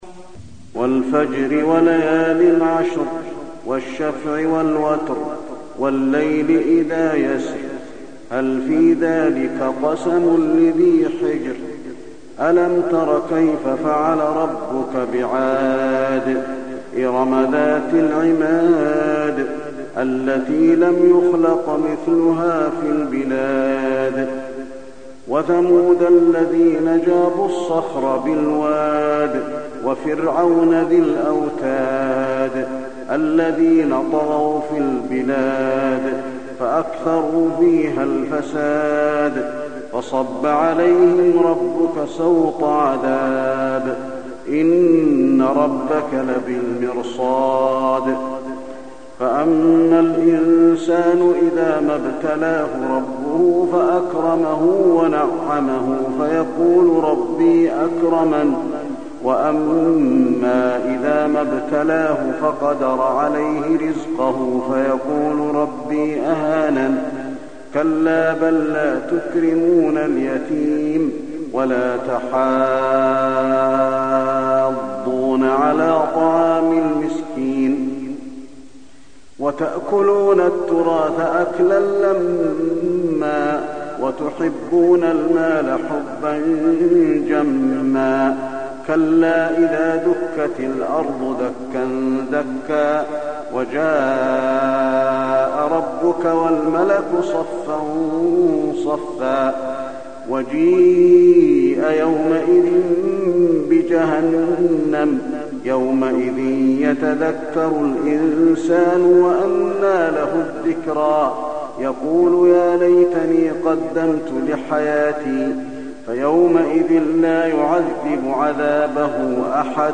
الفجر
المكان: المسجد النبوي الفجر